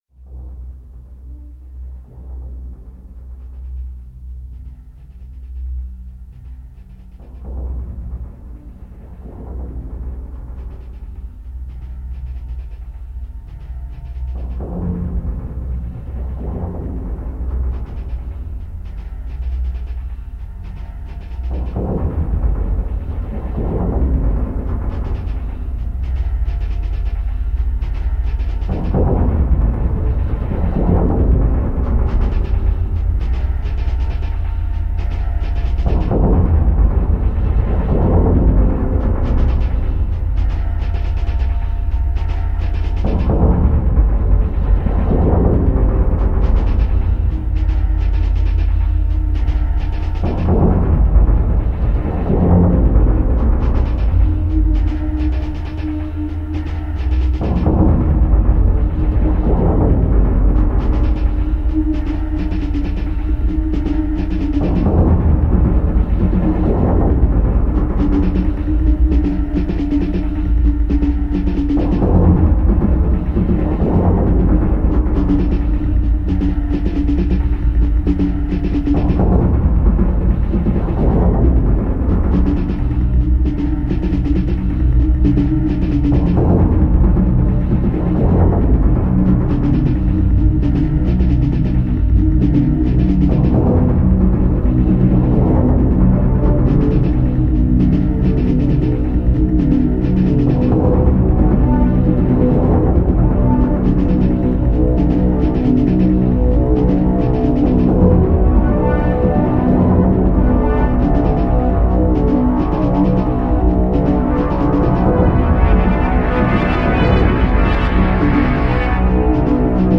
Possible Definition: Vacuum Pulse